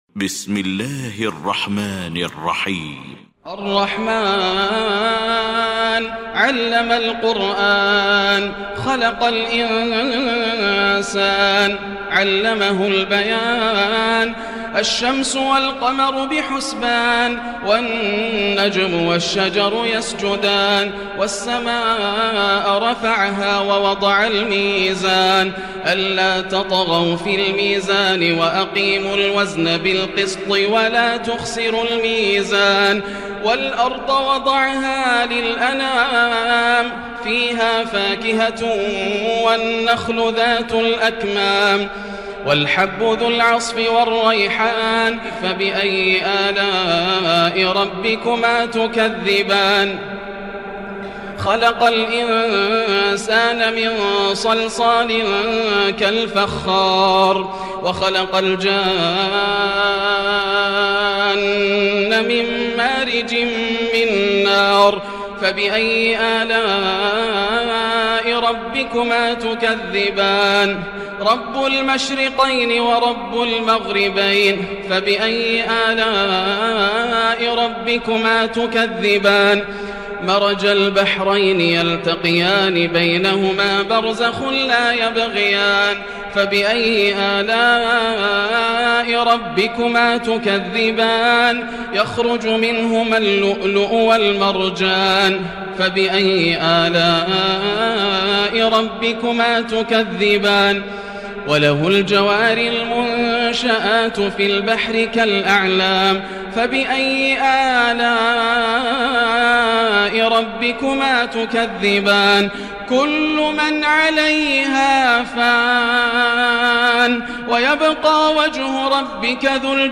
المكان: المسجد الحرام الشيخ: فضيلة الشيخ ياسر الدوسري فضيلة الشيخ ياسر الدوسري الرحمن